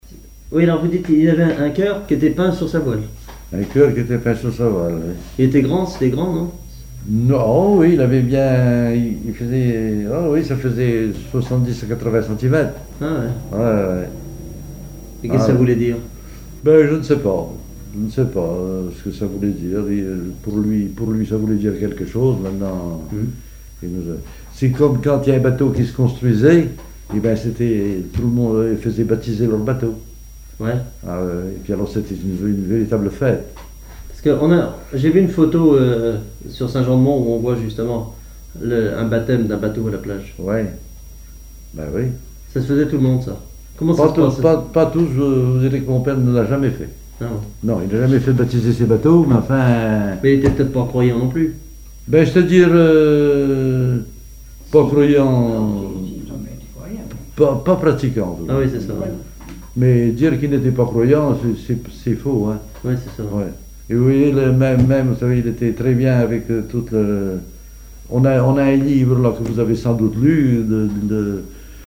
témoignages sur les activités maritimes locales
Catégorie Témoignage